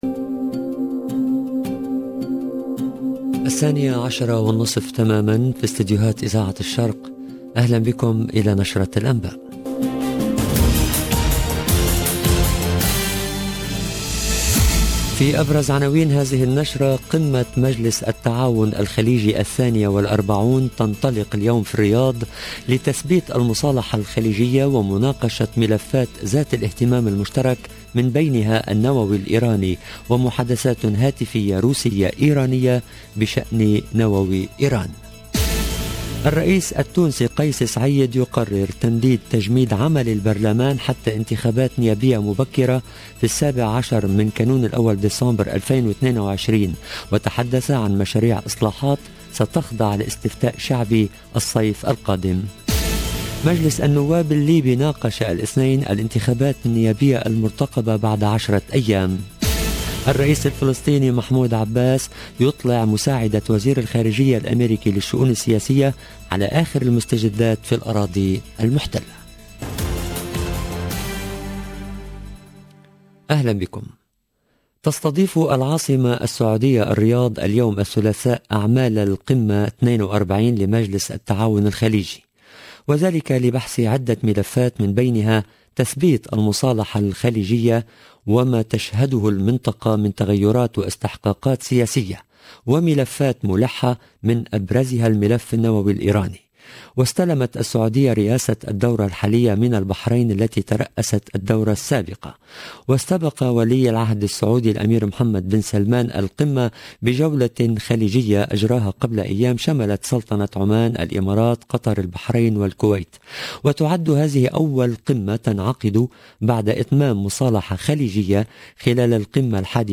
LE JOURNAL EN LANGUE ARABE DE MIDI 30 DU 14/12/21